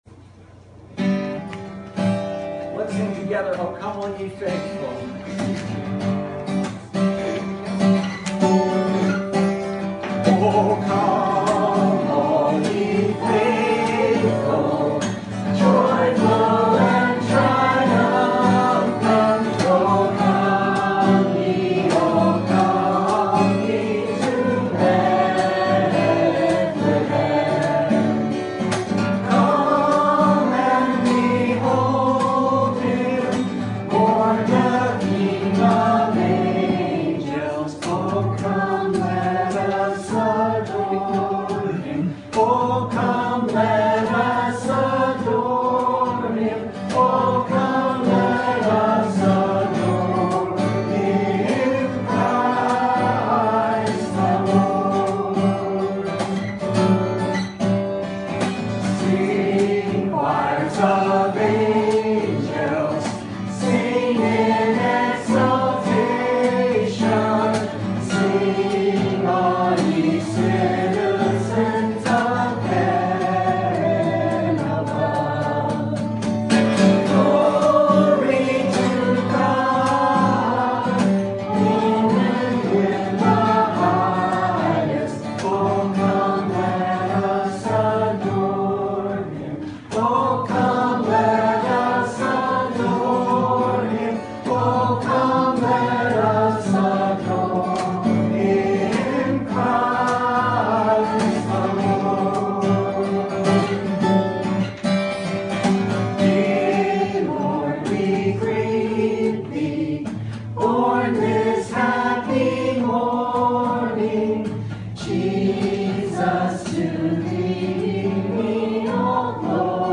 Series: Ruth and the Hope of the World Passage: Ruth 4:1-16 Service Type: Sunday Morning